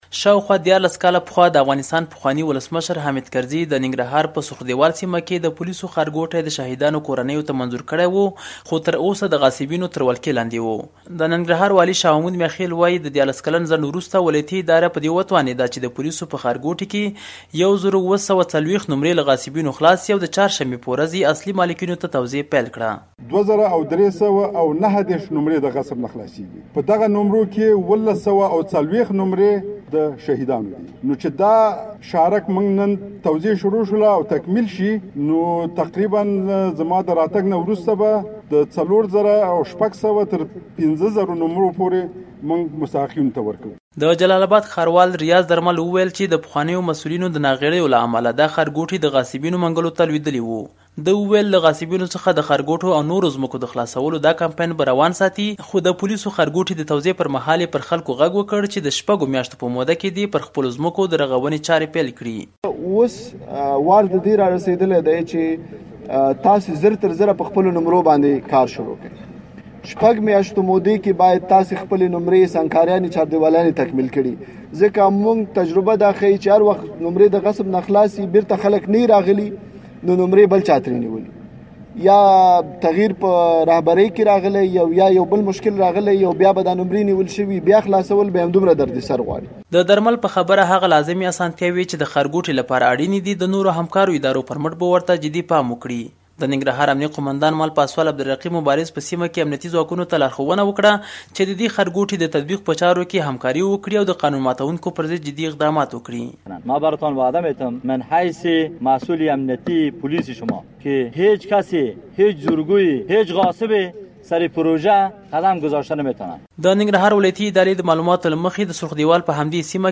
ننګرهار راپور